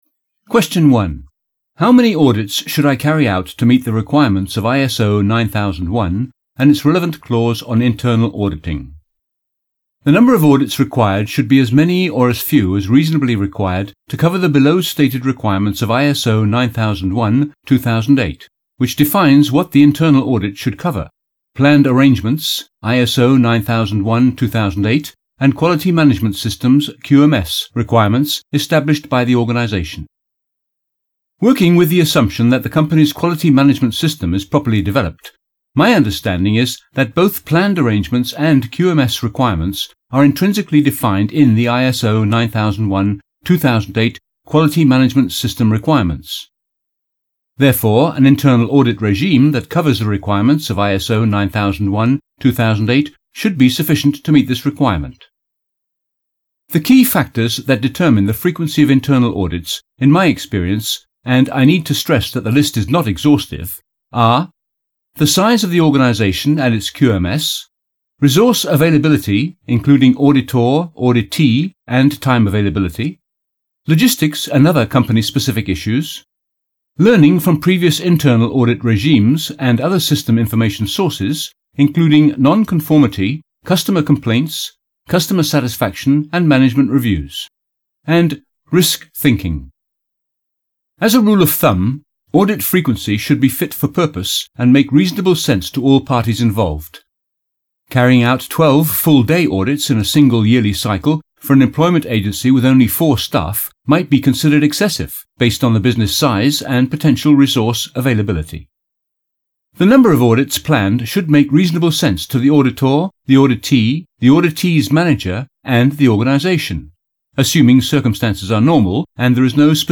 Factual Reads
This style of narration is best suited for business books, instructional books, tutorials and non-fiction in general.
Accent: English